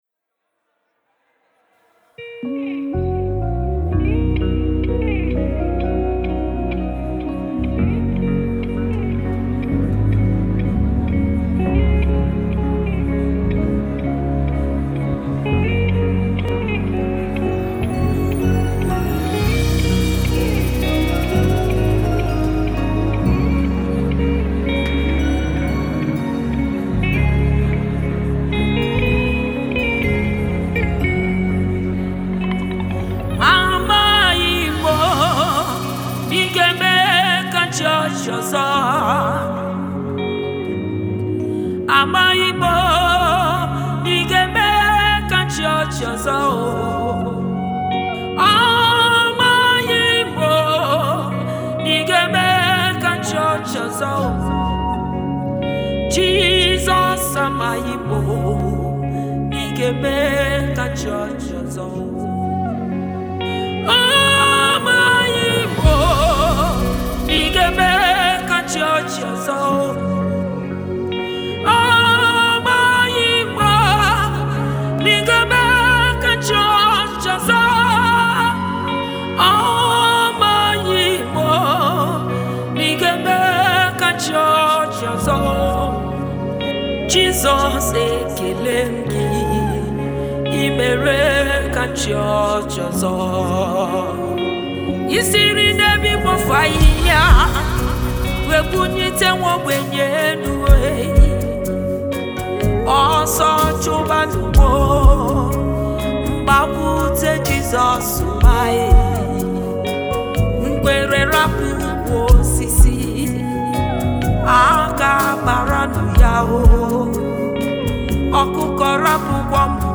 Nigerian Indigenous Gospel artist